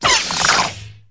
光剑5
描述：深沉的、起伏的嗡嗡声，用作光剑的空闲。使用数字生成/操纵的音调制作。
标签： 光剑 空闲
声道立体声